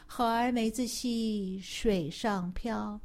Chinese Nursery Rhyme